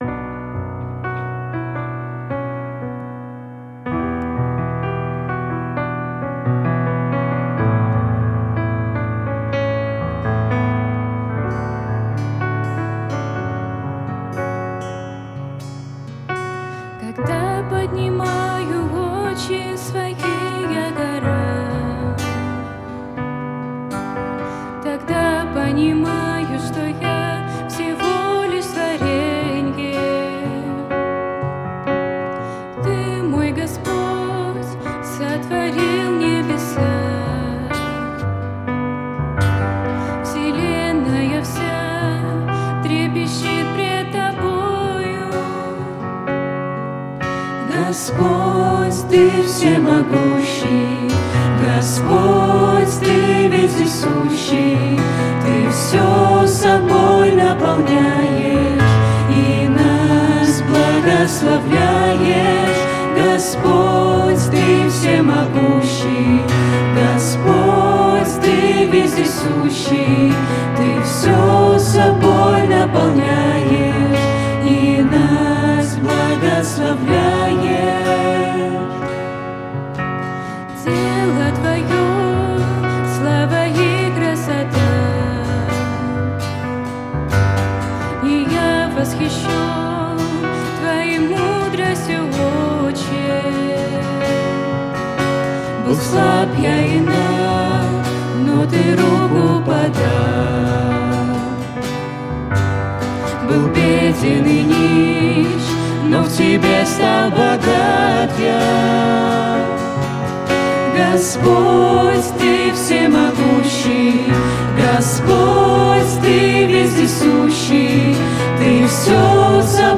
клавиши, вокал
ударные
скрипка
гитара